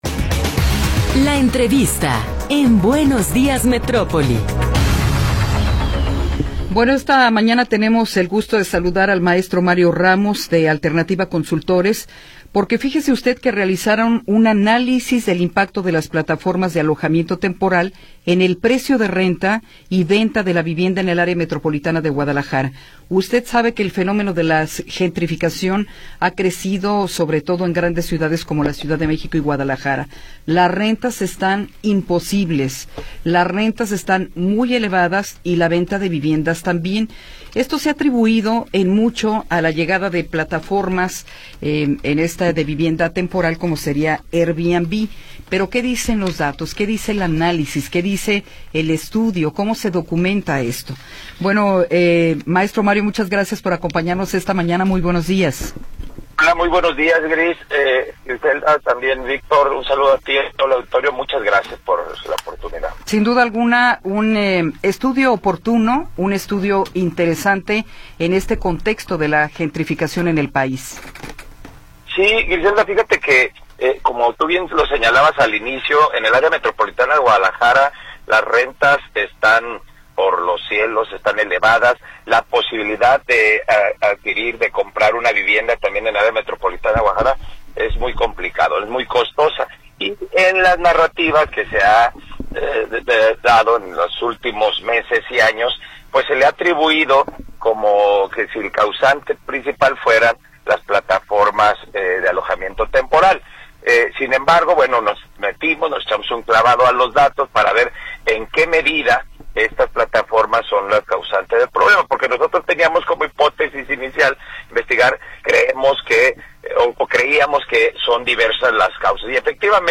Entrevista
entrevista.m4a